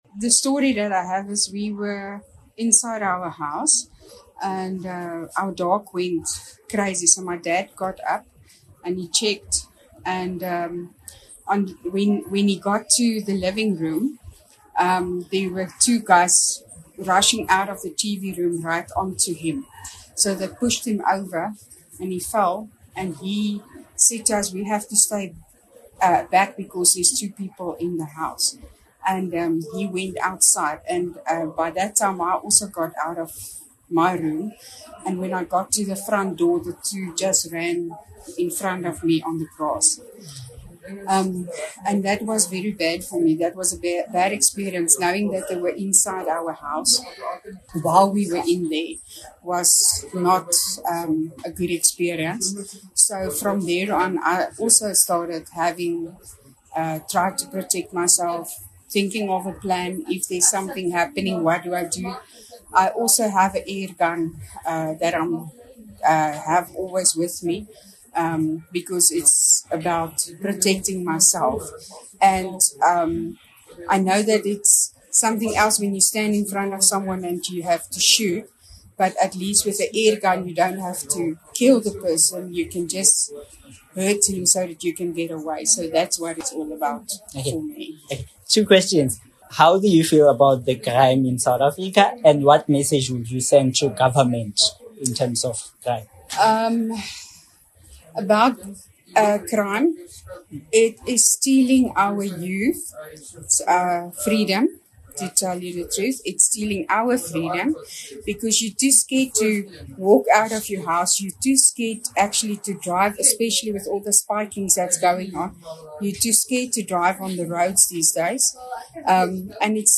A woman at a gun store details a disturbing experience that motivated her to buy a blank gun to protect herself and her family.